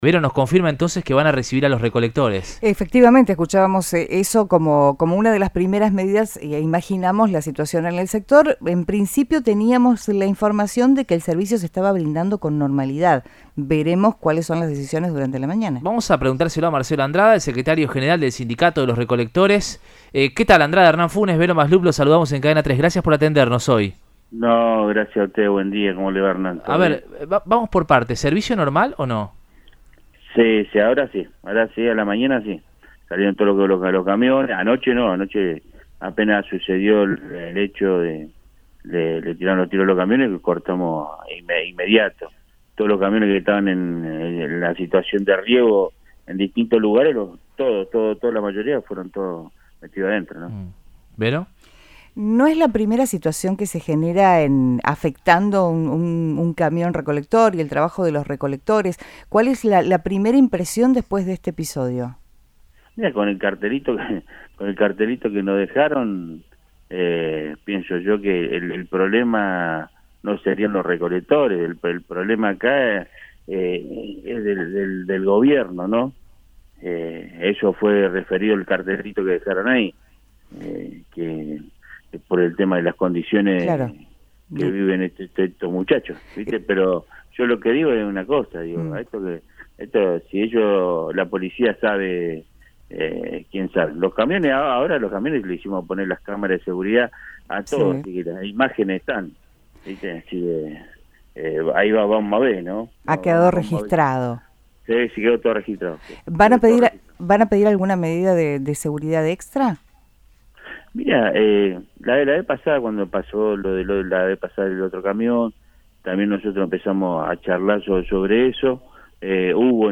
brindó una entrevista a Radioinforme 3